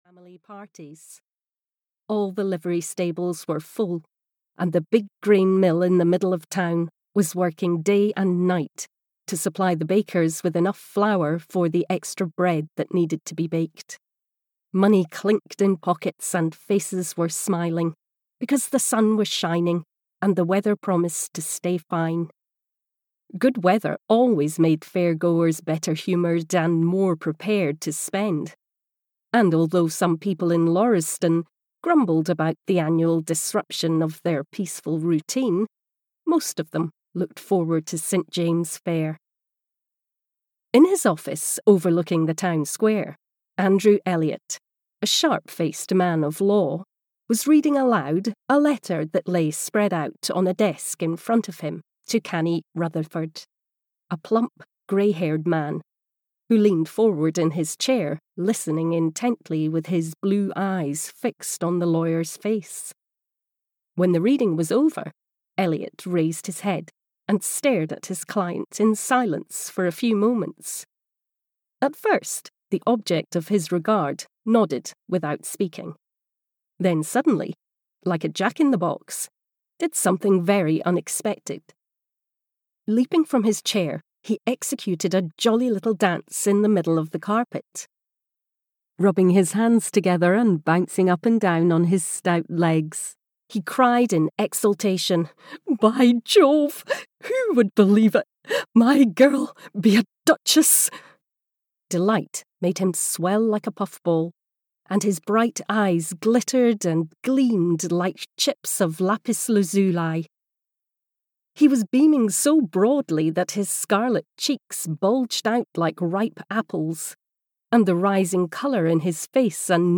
St James' Fair (EN) audiokniha
Ukázka z knihy